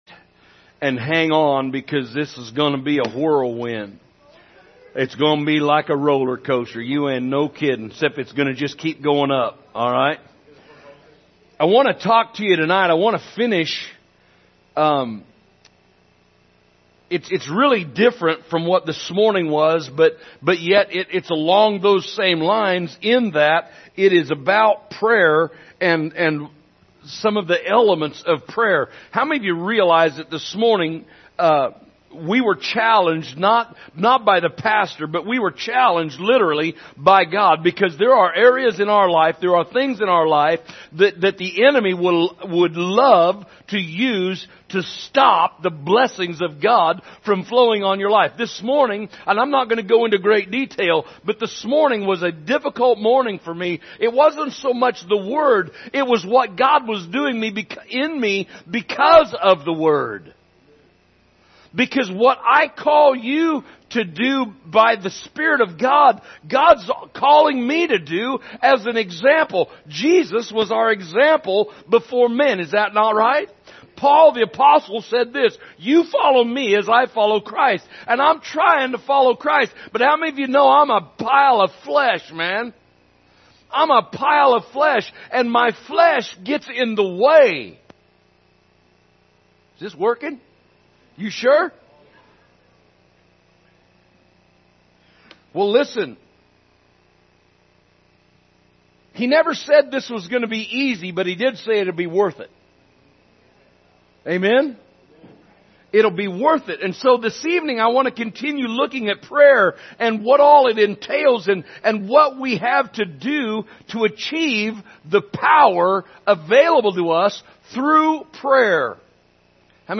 Sunday Evening Service September 25, 2022